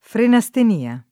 [ frena S ten & a ]